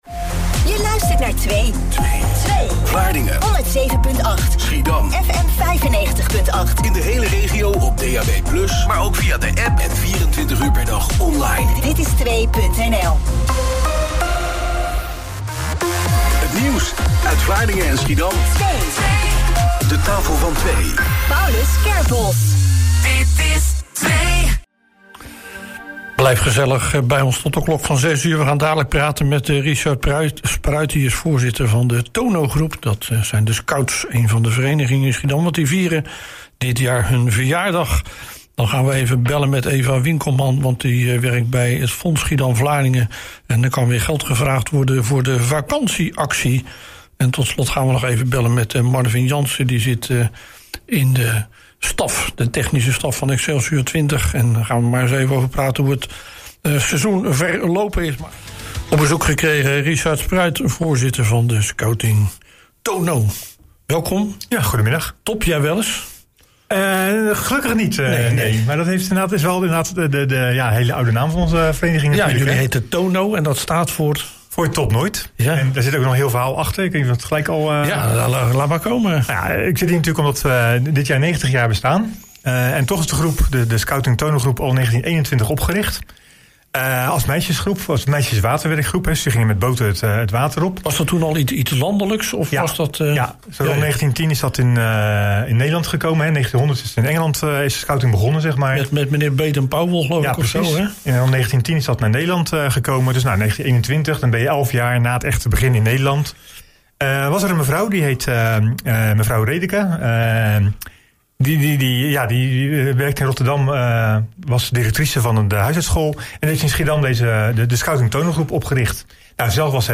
In een studiointerview